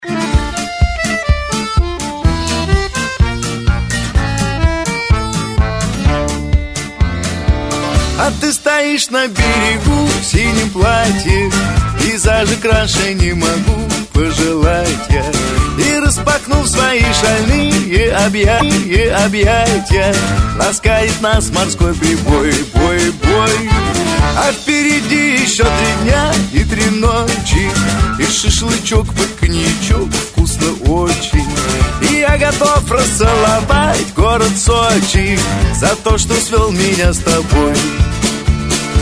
из Шансон